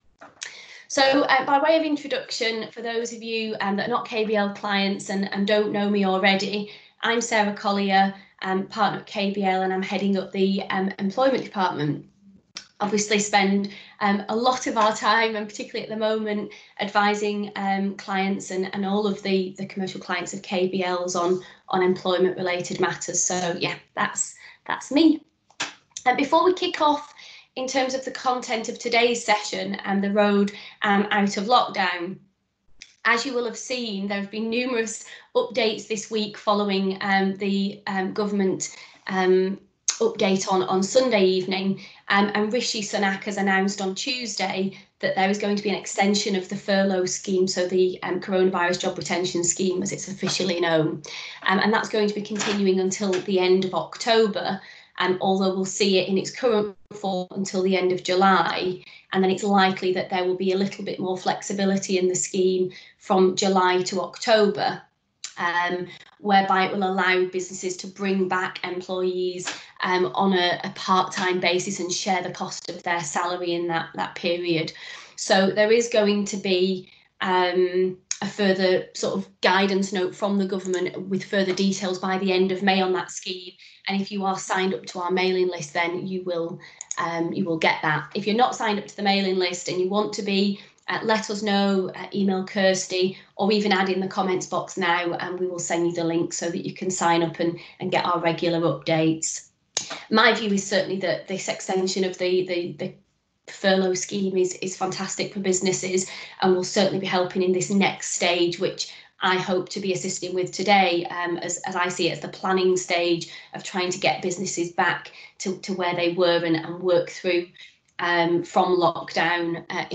Webinar_-Road-out-of-Lockdown-online-audio-converter.com_.mp3